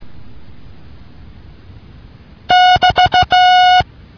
IL COLLEGAMENTO IN TELEGRAFIA